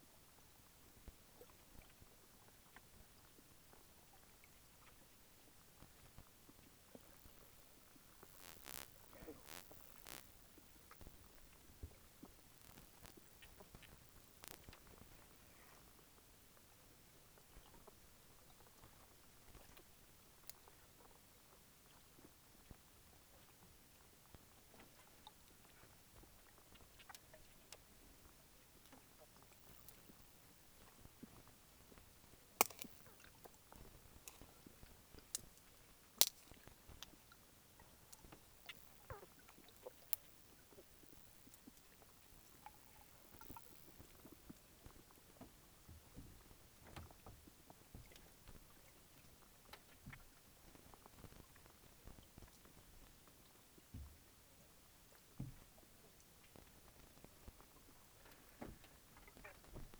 River dolphin spectrogram at night Spectrogram of sound attributed to the pink river dolphin as recorded at night on 24 July 2012 during a caiman survey.